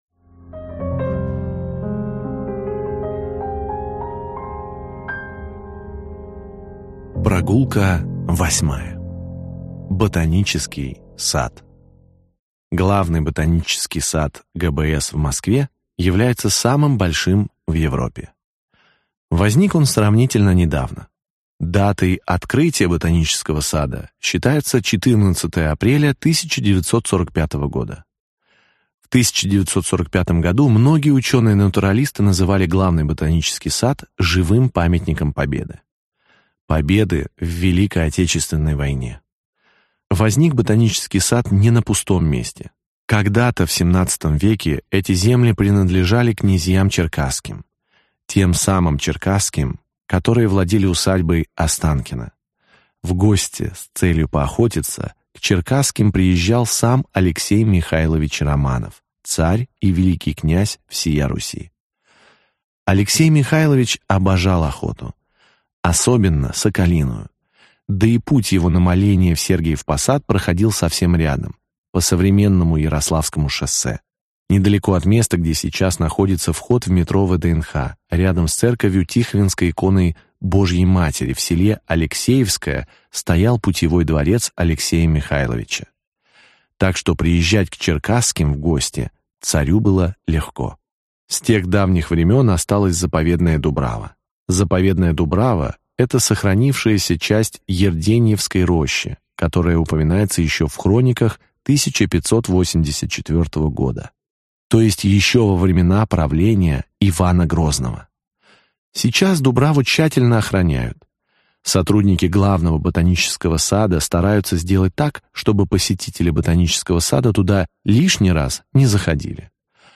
Аудиокнига 8 заповедных мест в Москве, куда можно доехать на метро. Глава 8. Ботанический сад | Библиотека аудиокниг